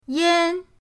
yan1.mp3